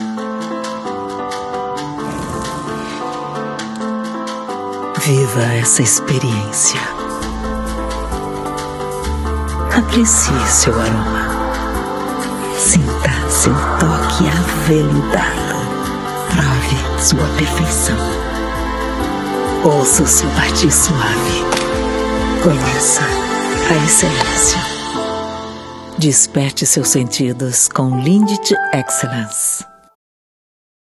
Sensual/Chocolates Lindt
Voz Padrão - Grave 00:31
Owns an at home recording studio.